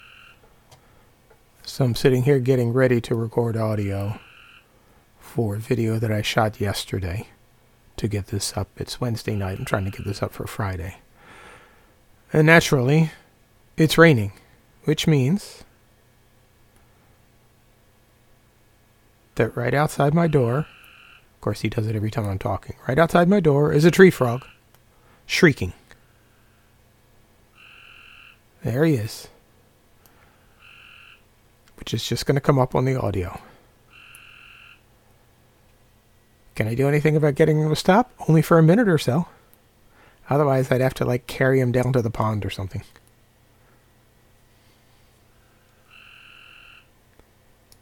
Literally a meter from the door
pair of noisy copes grey treefrogs Dryophytes chrysoscelis calling during rain
AssholeFrogs.mp3